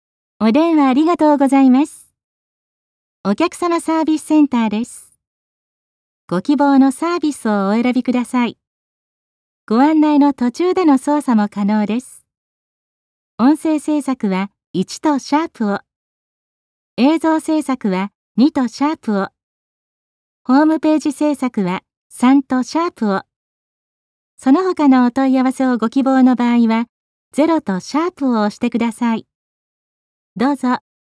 IVR（自動音声応答システム）用音声ガイダンス制作
プロのナレーター/声優を起用することで、合成音声では実現できないナチュラルで表現豊かなアナウンスを提供致します。
• コールセンター向けのIVRアナウンス